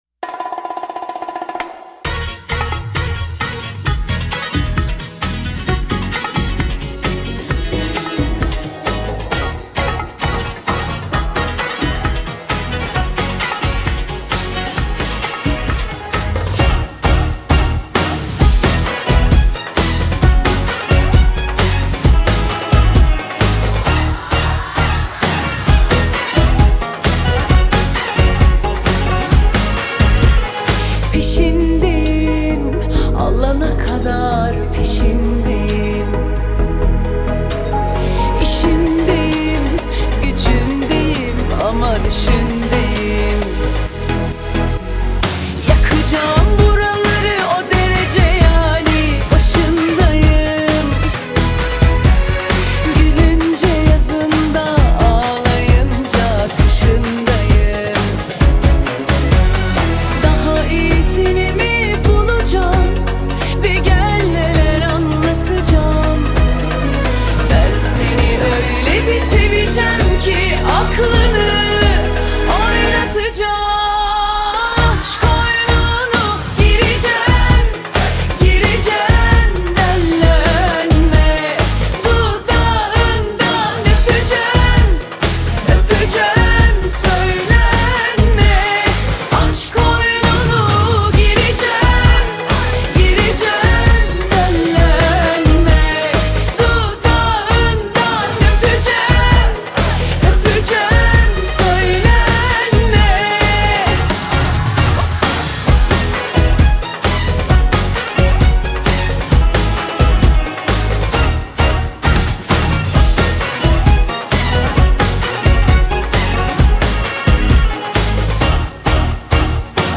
آهنگ ترکیه ای آهنگ شاد ترکیه ای آهنگ هیت ترکیه ای